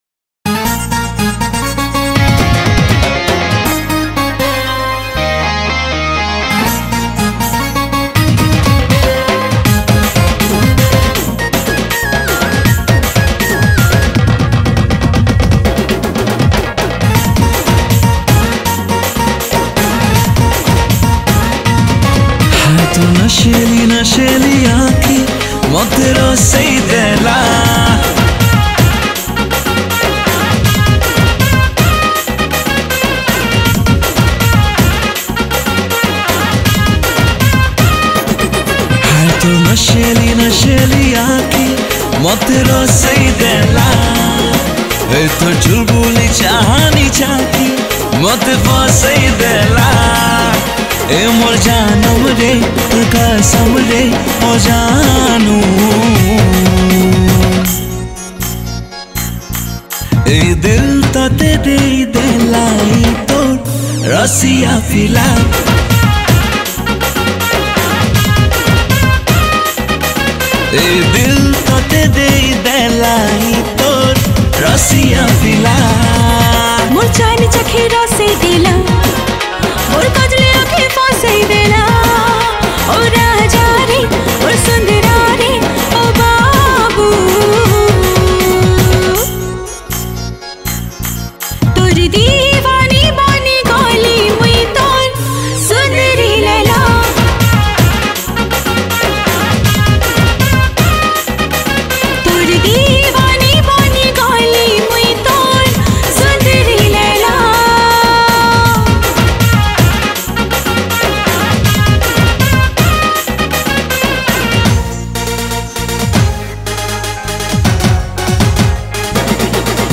New Sambalpuri Song 2022